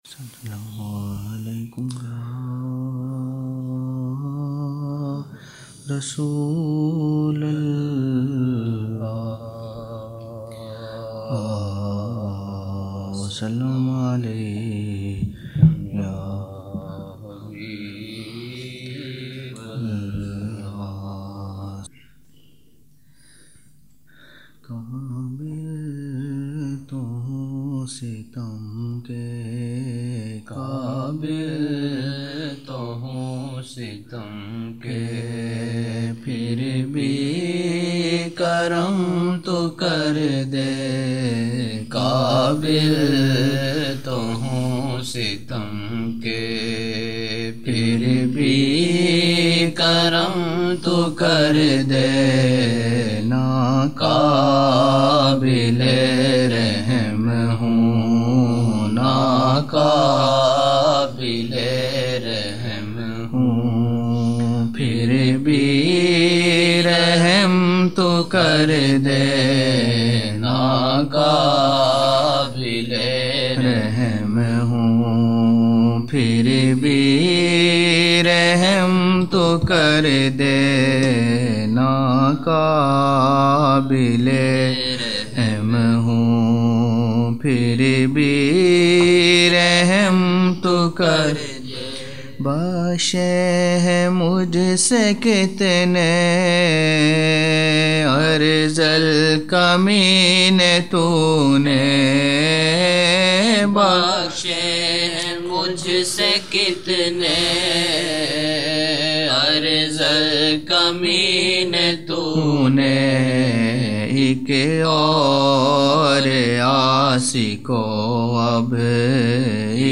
18 November 1999 - Fajar mehfil (10 Shaban 1420)
Naat shareef: - Qabil tou hoon sitam ke phir bi karam tou karday - Sara aalam Shaha SAW rajdani teri
Chand naatia ashaar